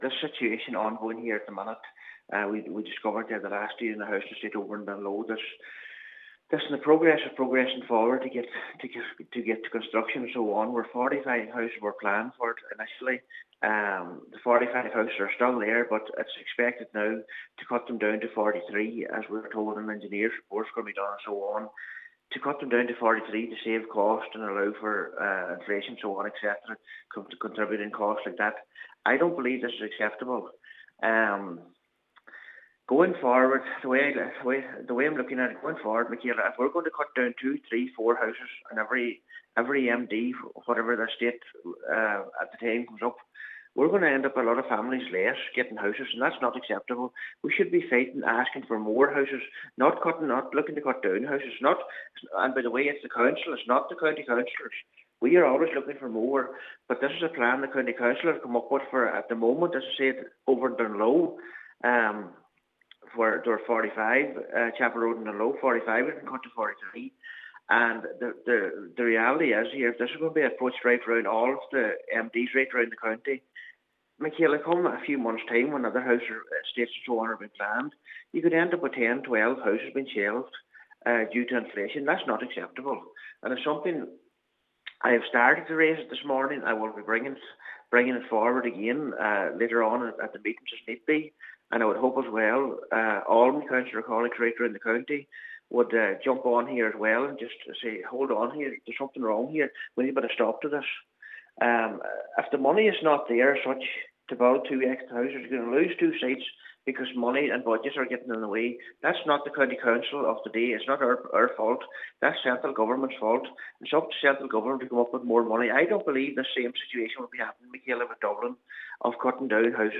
He says at a time when people are crying out for houses it is totally unacceptable: